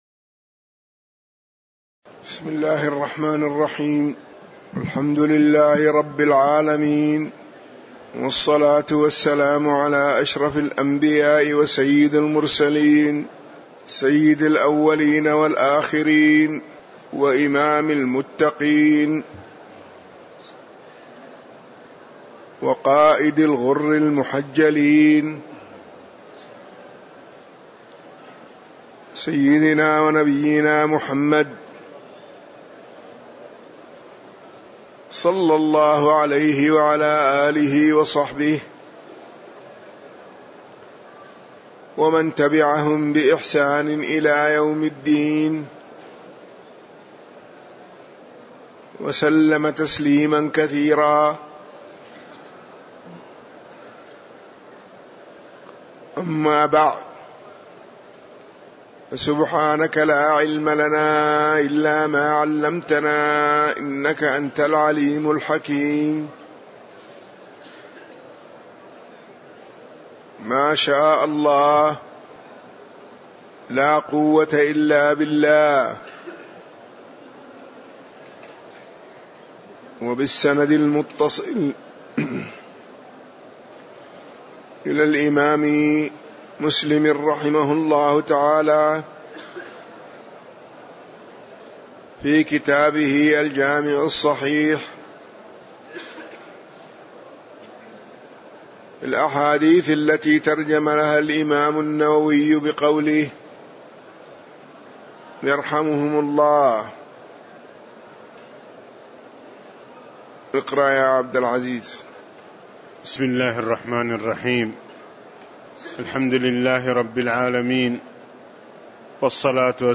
تاريخ النشر ٤ ذو القعدة ١٤٣٧ هـ المكان: المسجد النبوي الشيخ